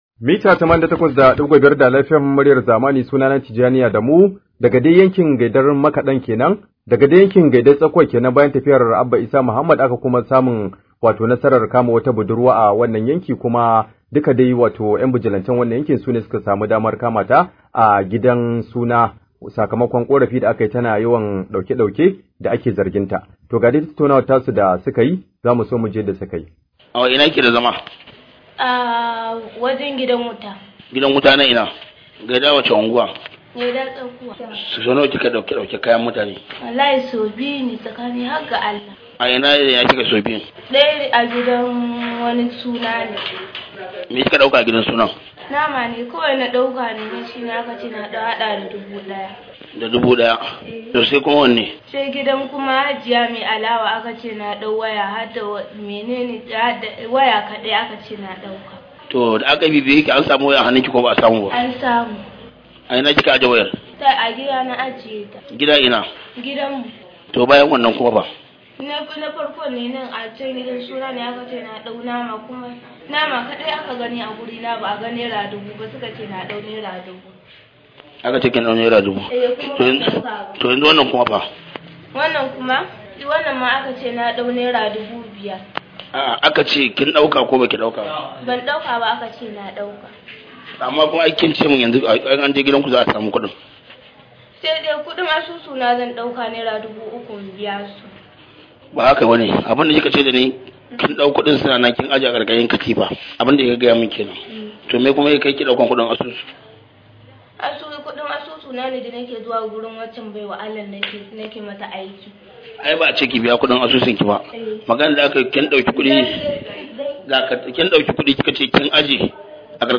Rahoto: A na zargin Budurwa da satar naman gidan Suna